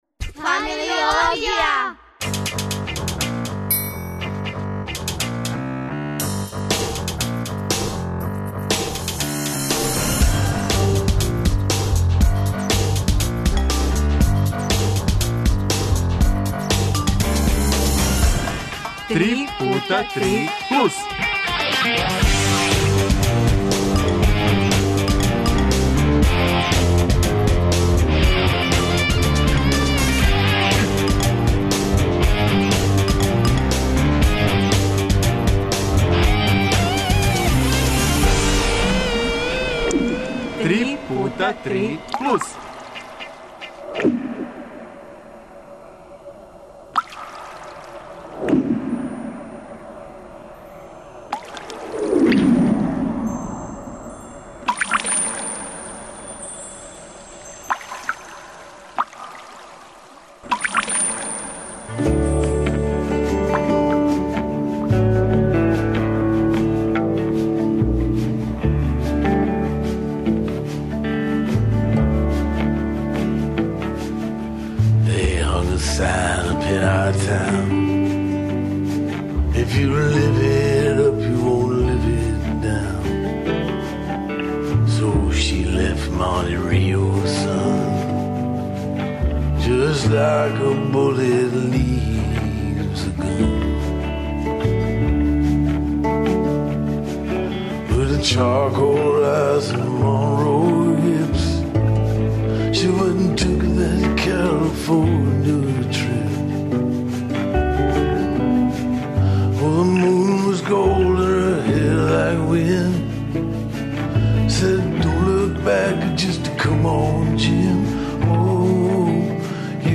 Не, данас, уз џез, хитамо ка ноћи с највише звукова и највише тишина.